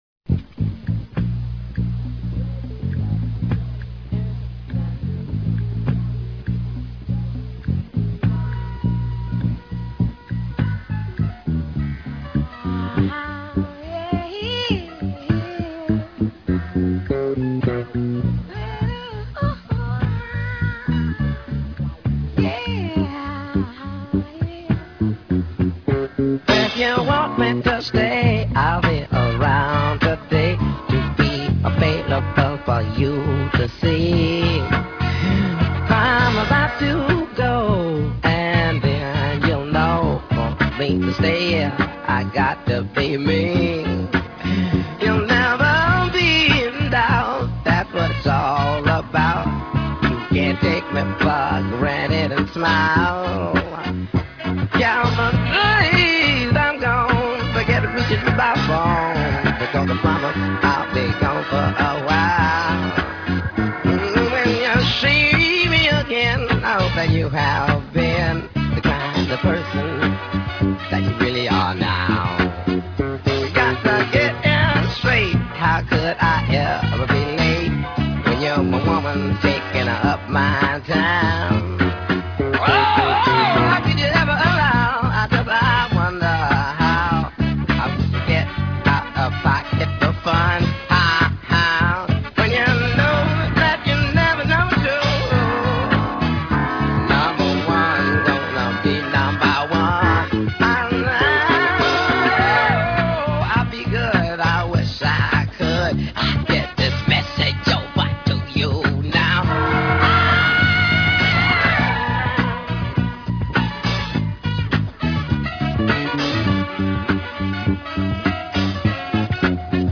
lead vocals, keyboards, everything
guitar, background vocals
lead and background vocals
drums
saxophone
trumpet
bass guitar